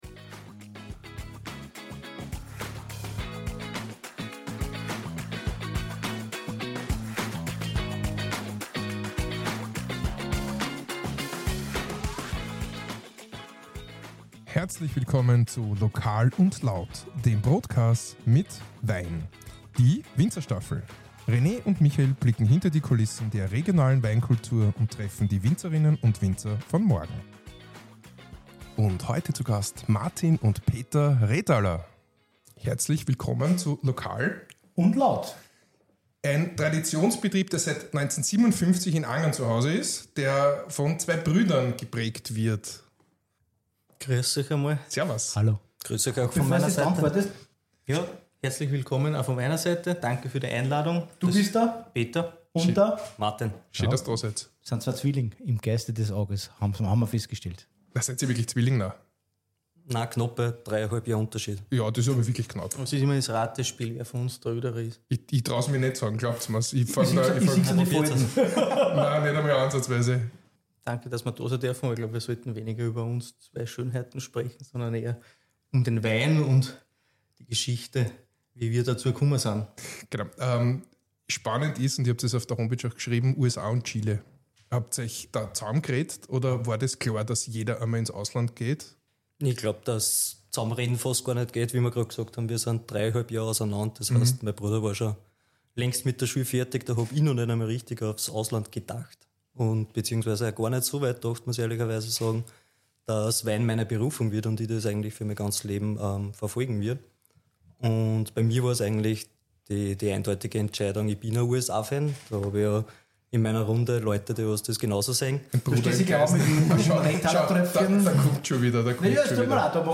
Herzlich Willkommen, bei Lokal und Laut, dem Brotkas mit Wein. Ein Ort für Lokale Gäste und Geschichten die das Leben schreibt, mit einer Prise Humor garniert mit unverschämter Ehrlichkeit.